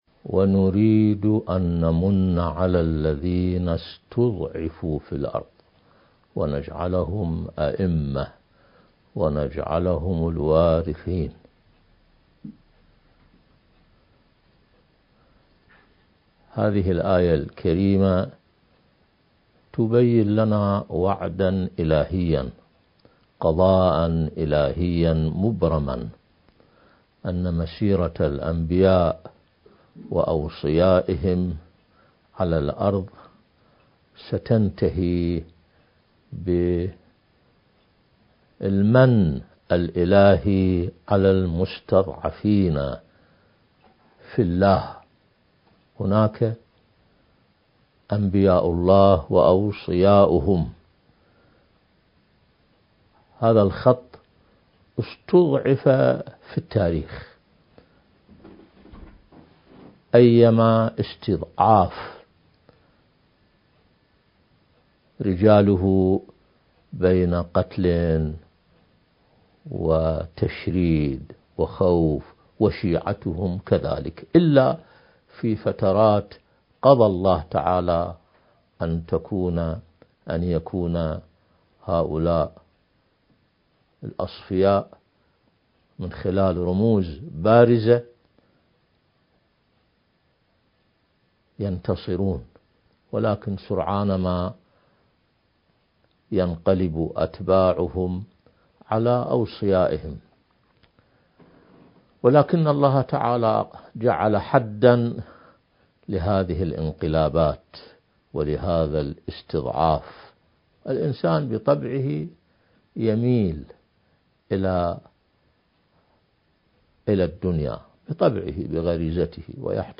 المكان: كلية الإمام الكاظم (عليه السلام) - ميسان